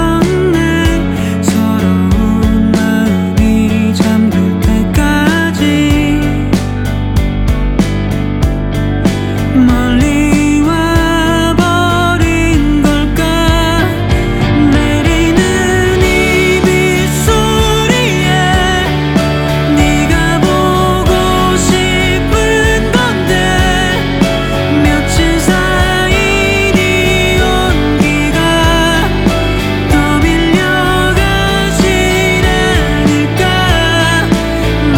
2025-07-02 Жанр: Рок Длительность